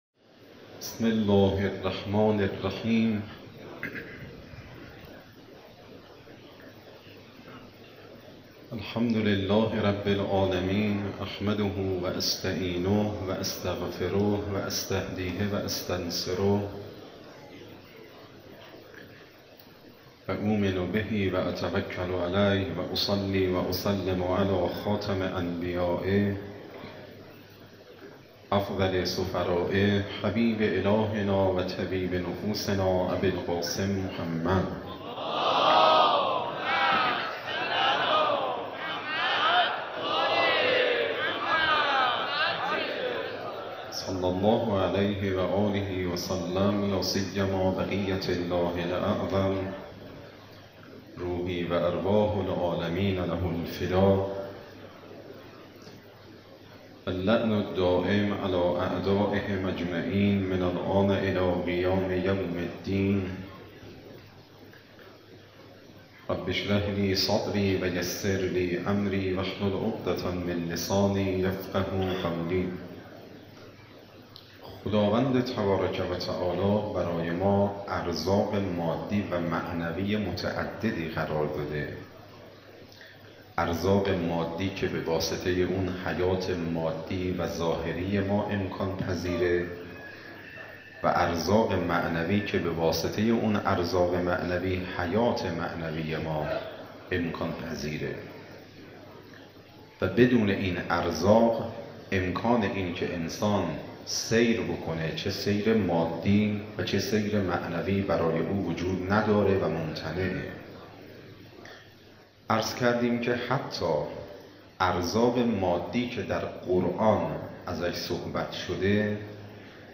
Moharrame 93, Shabe 02, Sokhanrani.mp3